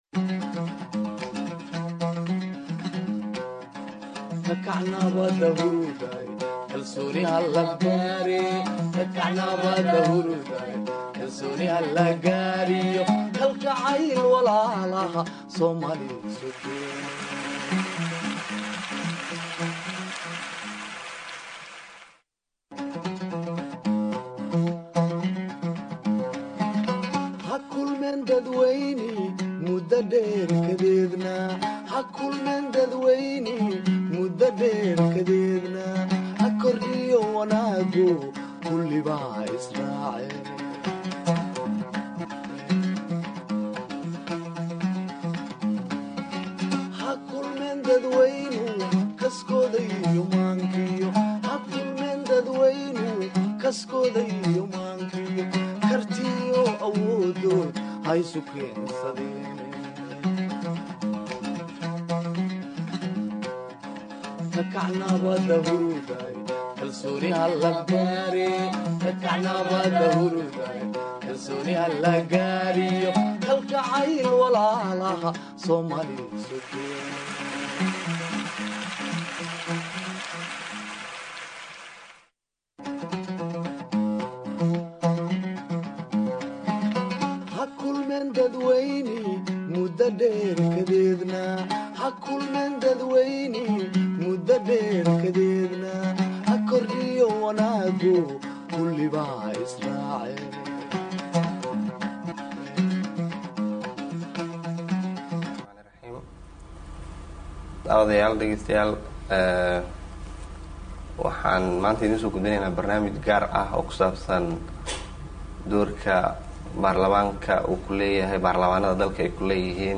Dooddaan oo ay ka qeybgaleen Xildhibaanno ka kala socday Barlamaanka Federaalka Soomaaliya iyo kuwa dowlad goboleedyada ayaa diiradda lagu saaray